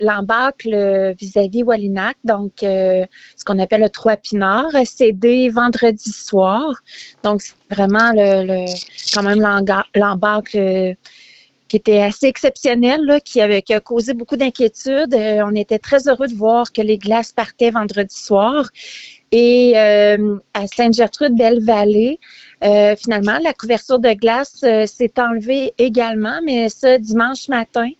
En entrevue, la mairesse, Lucie Allard, est revenue sur les nouvelles encourageantes des derniers jours.